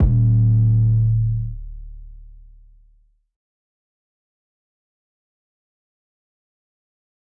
Metro 808s [Powerful].wav